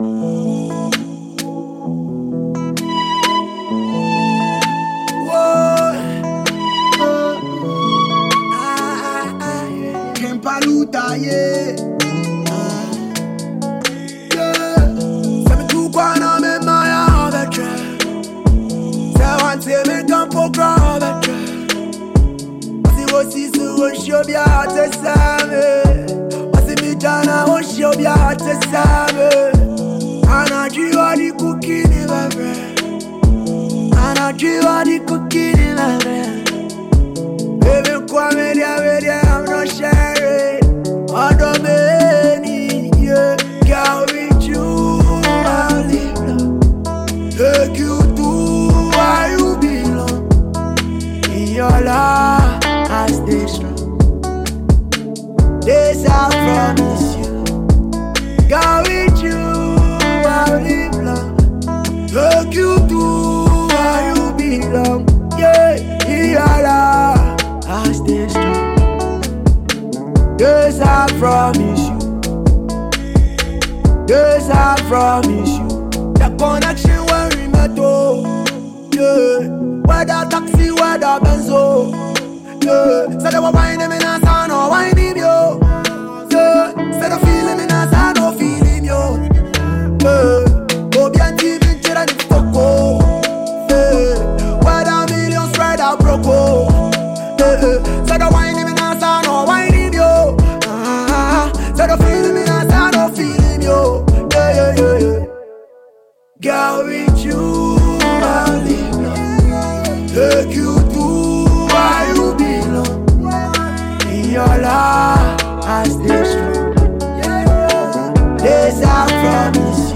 With its heartfelt lyrics and infectious rhythm